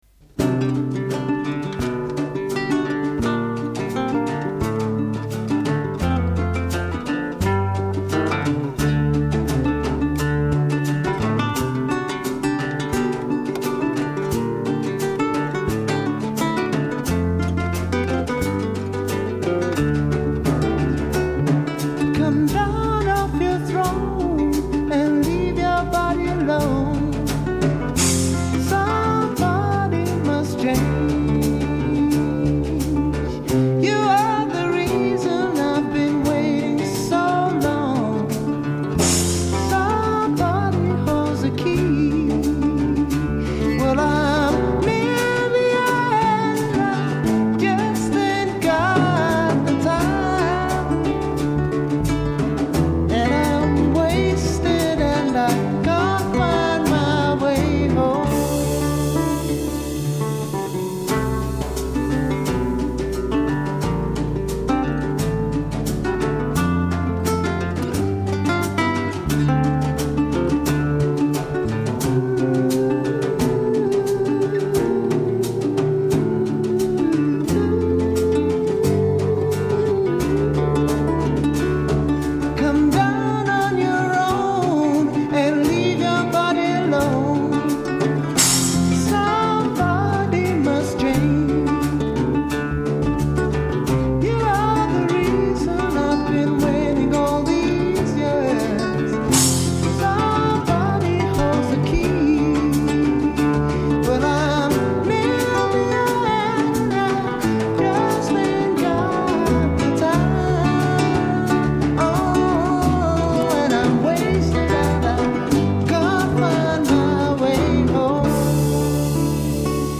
vocal and guitar
bass guitar
drums
Coda 12 Ritornello material intensified for final cadence. d